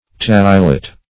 Search Result for " tachylyte" : The Collaborative International Dictionary of English v.0.48: Tachylyte \Tach"y*lyte\, n. [Gr. tachy`s quick + ly`ein to dissolve.]